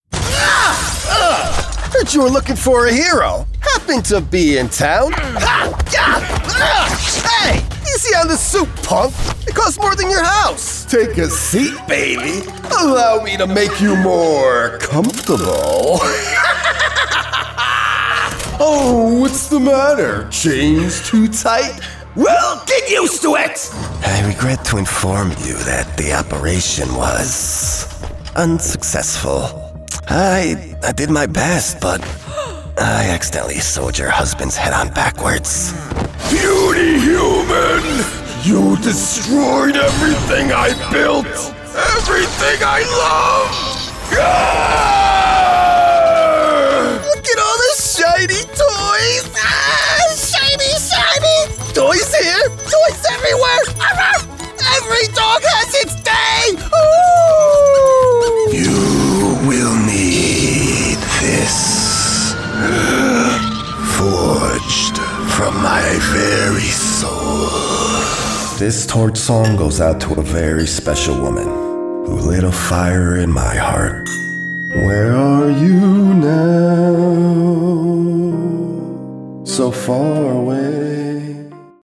Versatile voice actor for characters, video games, commercials, narration, and animation. Professional, dynamic voice for all your project needs.